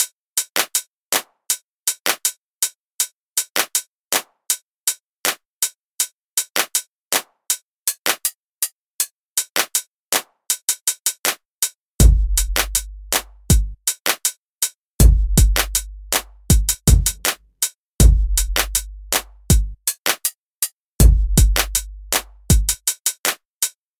说唱节拍鼓和热门类型的嘻哈音乐
Tag: 80 bpm Rap Loops Drum Loops 4.04 MB wav Key : Unknown